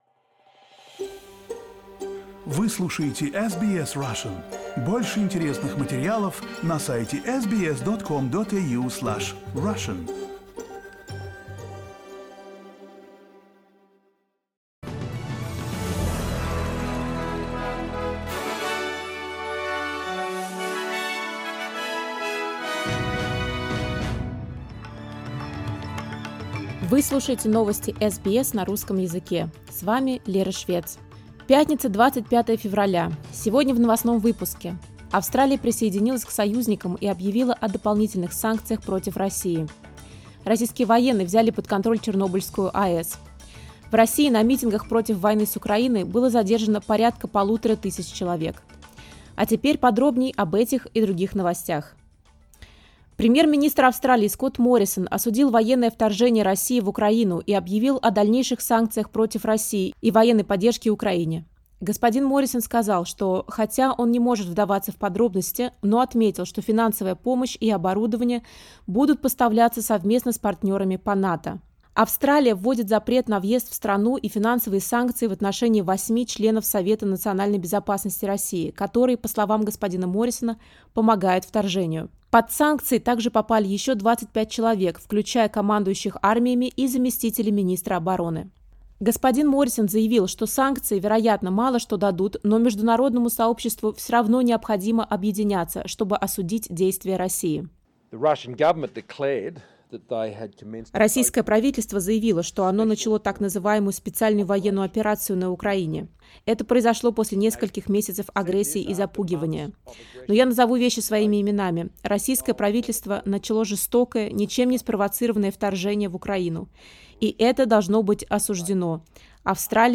Listen to the latest news headlines in Australia from SBS Russian.